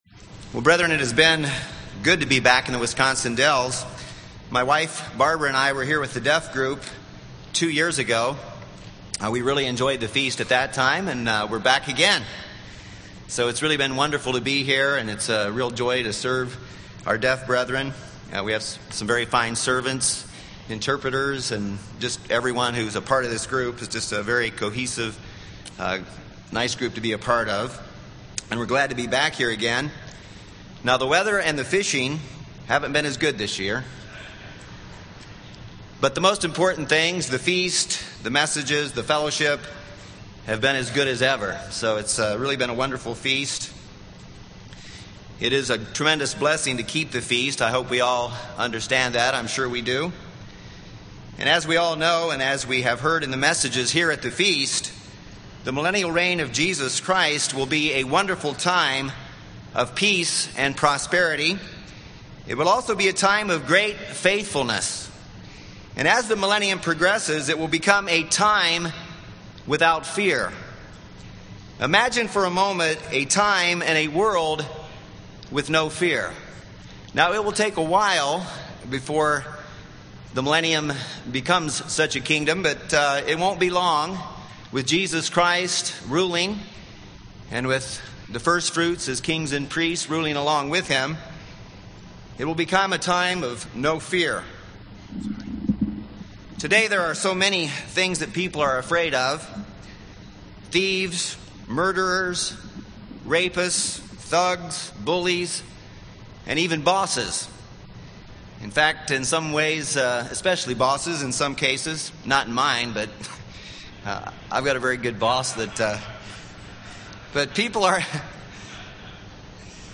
This sermon was given at the Wisconsin Dells, Wisconsin 2009 Feast site.